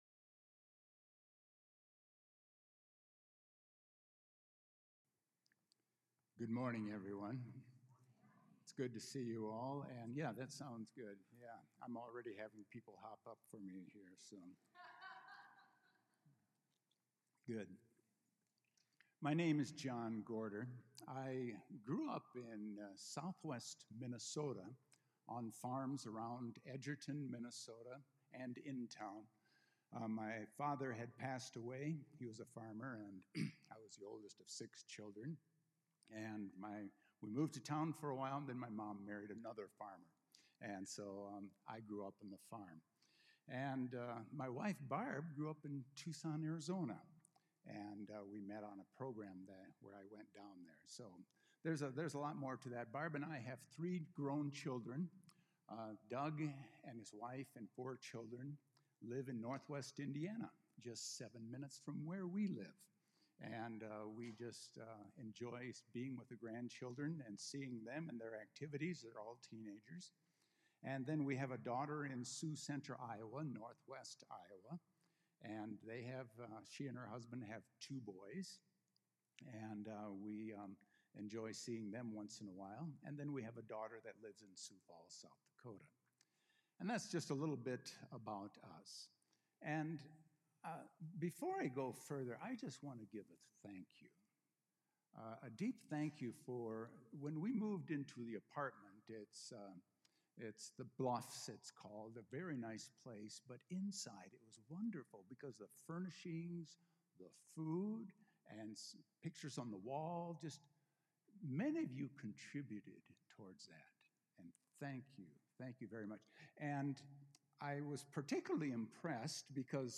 A message from the series "Philippians